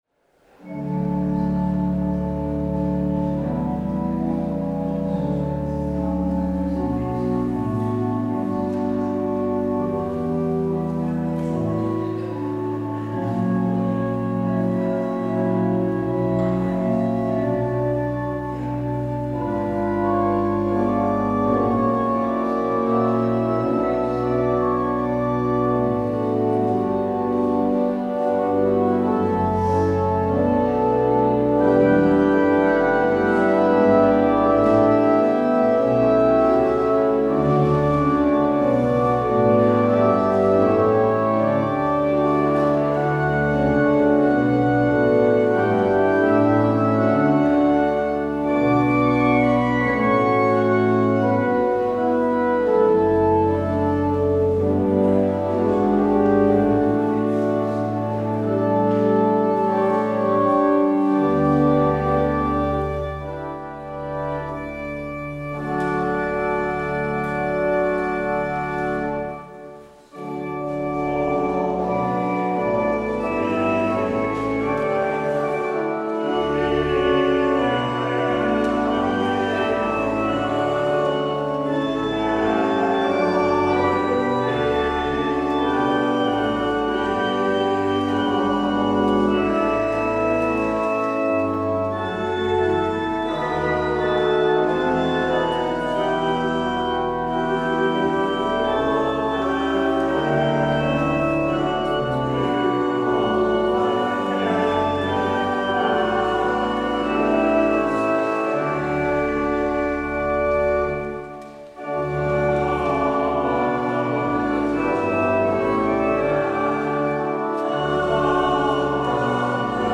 Als slotlied hoort u uit het Nieuwe Liedboek – lied 454 ‘De mensen die gaan in het duister’.